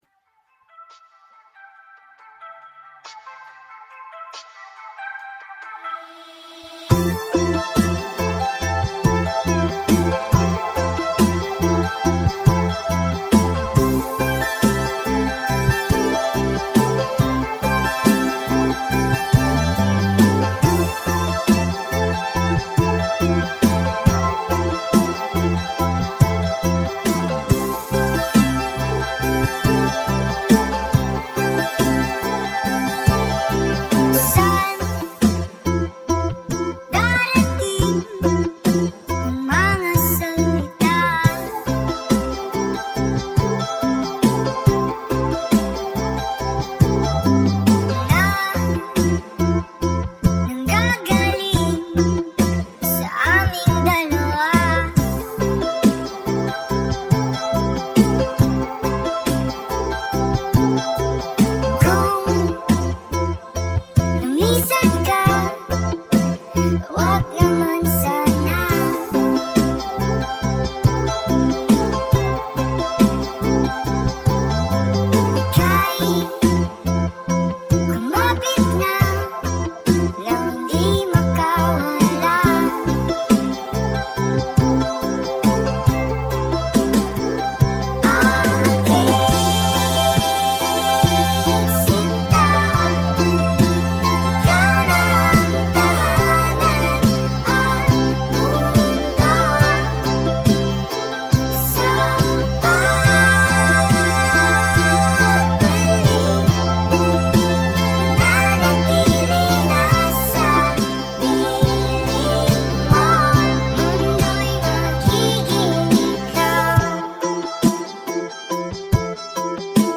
indie band